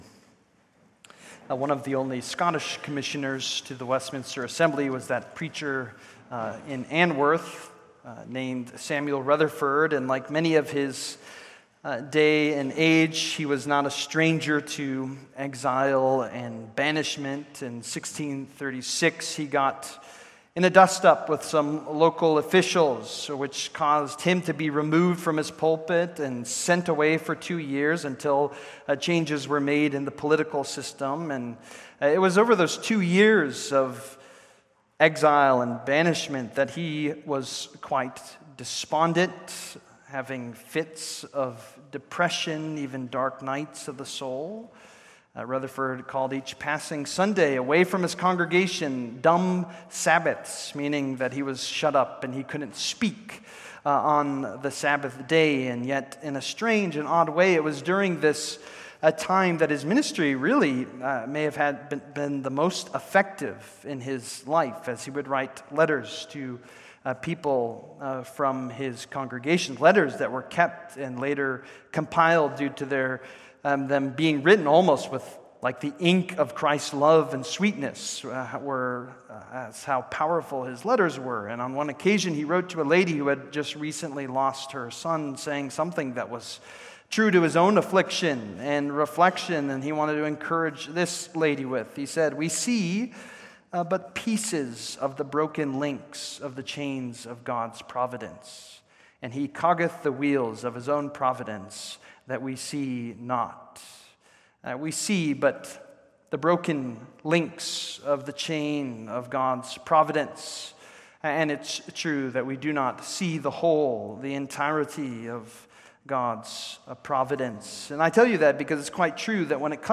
Service: Sunday Evening